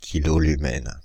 Ääntäminen
France (Île-de-France): IPA: /ki.lɔ.ly.mɛn/